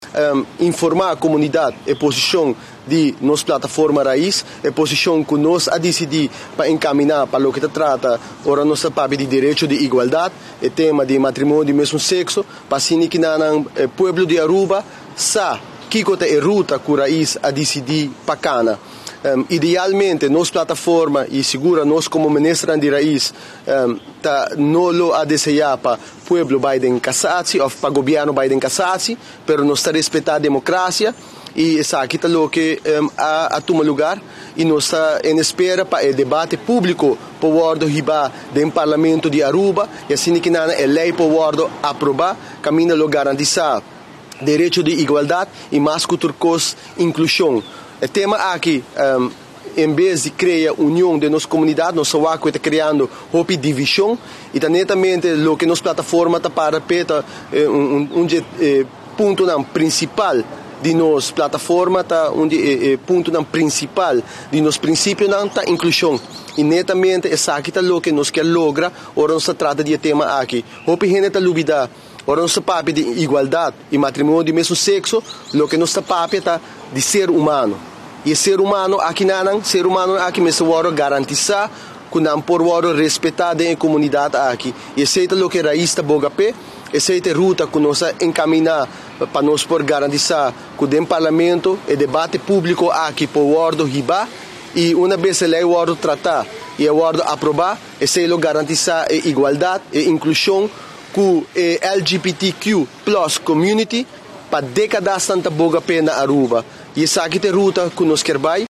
Lider di partido RAIZ Ursell Arends a yama un conferencia pa asina trece claridad loke ta partido RAIZ su pensamento riba decision di gobierno pa bay casacion. Segun Lider di partido RAIZ no a desea pa gobierno bay den cassatie, pero ta respeta democracia y ta laga e proceso cana su caminda.